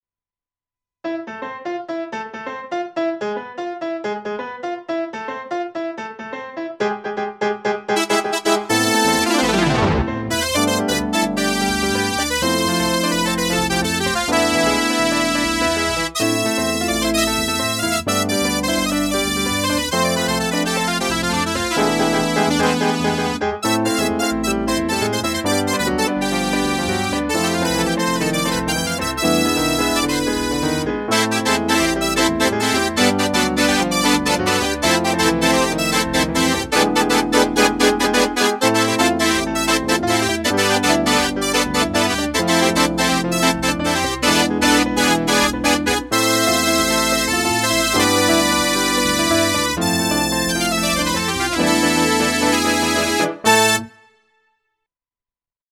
ox-brass.mp3